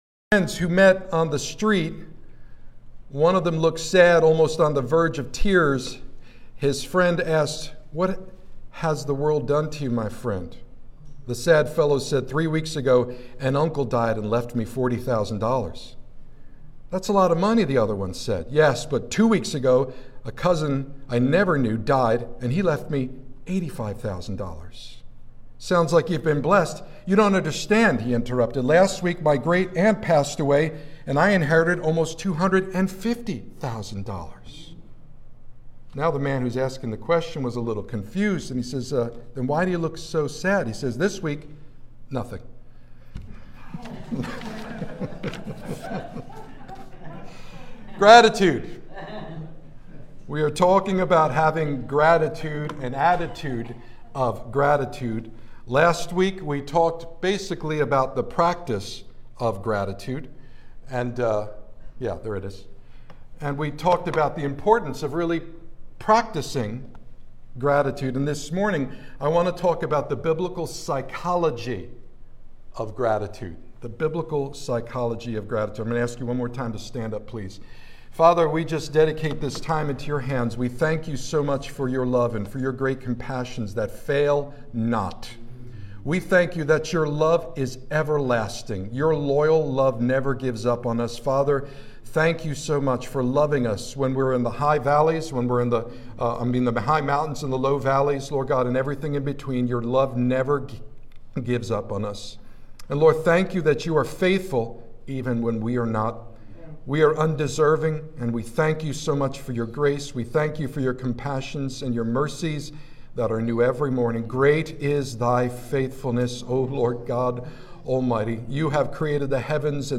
Sermons | Our Father's House International Ministry (OfHim)